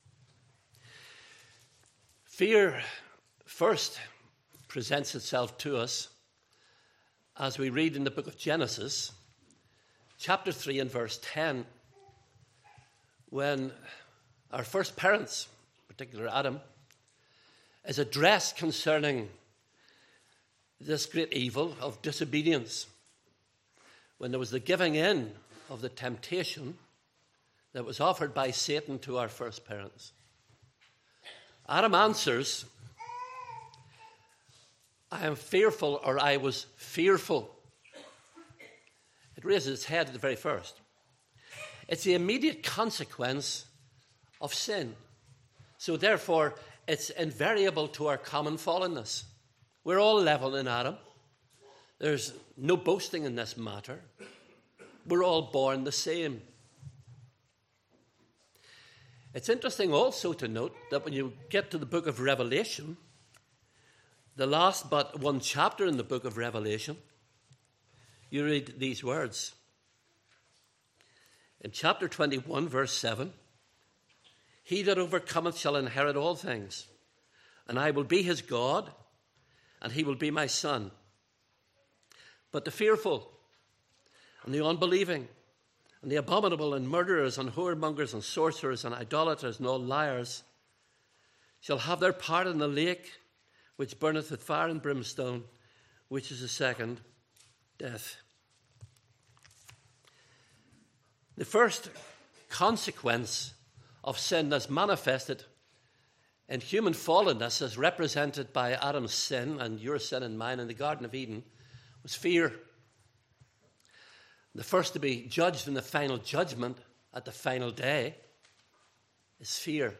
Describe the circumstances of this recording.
7- 22:2 Service Type: Morning Service Bible Text